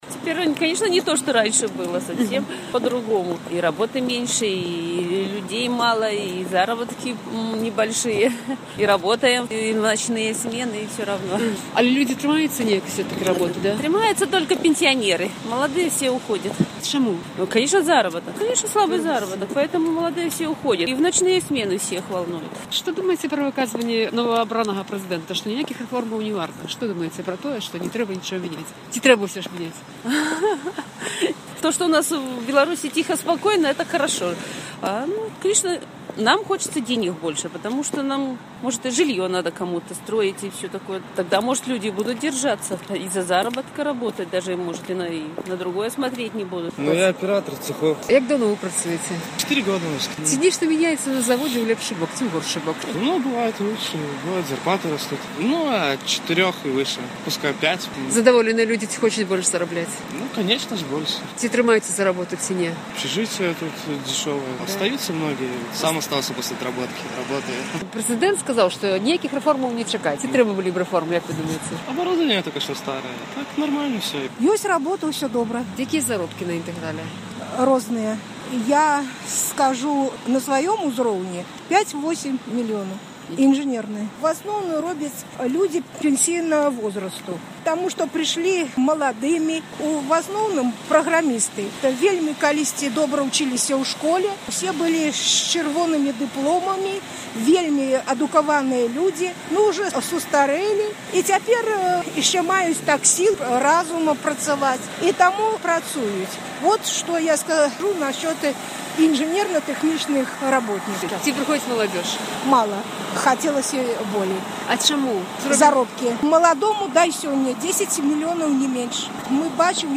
Апытаньне каля прахадной «Інтэгралу». Як ставіцеся да заявы Лукашэнкі, што ніякіх рэформаў ня будзе?
На гэтыя пытаньні працоўныя «Інтэграла» адказваюць каля прахадной прадпрыемства.